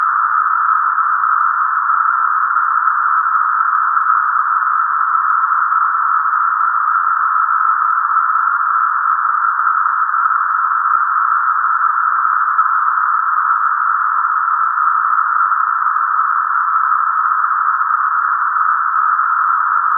hum of cicadas calling from high in the trees. There were clearly thousands of them in this neighborhood, and soon we started seeing small clusters of cicadas sitting on trees and bushes.
magicicada_septemdecim.mp3